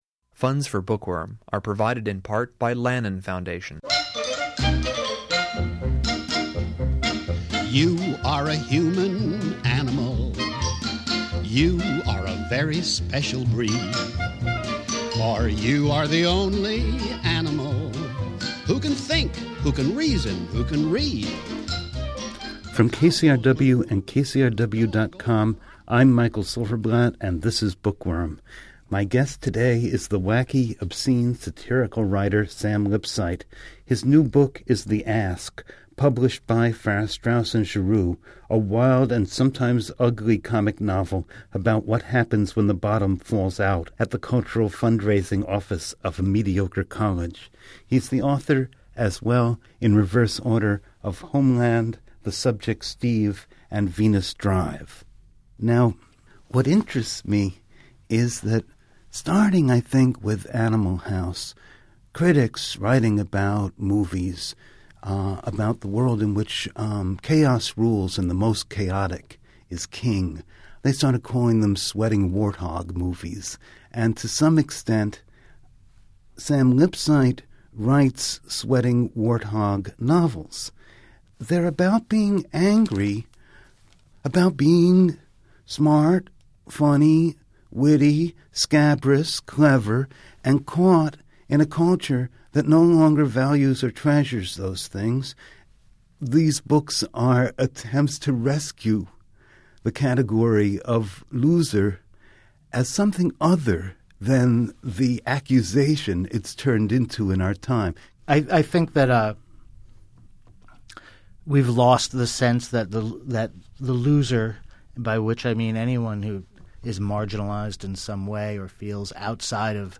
Note: This interview contains language that some listeners may find offensive.